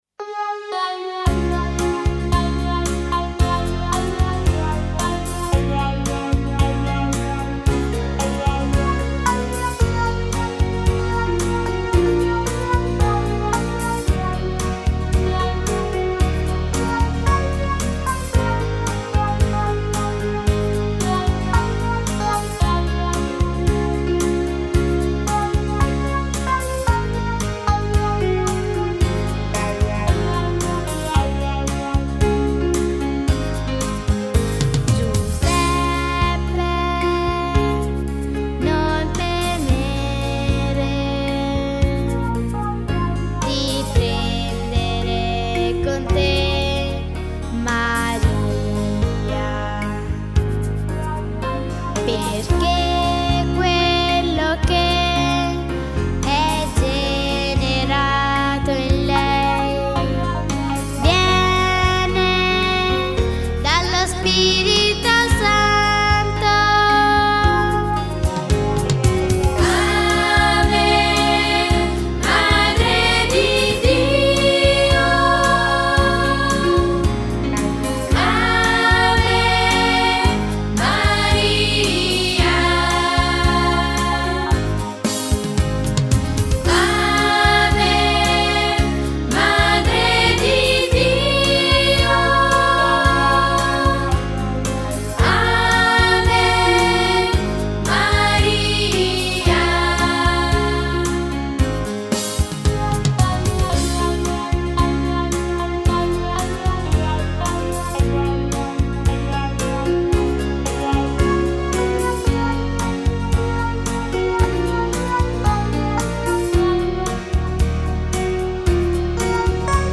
Canto per Rosario e Parola di Dio: Giuseppe non temere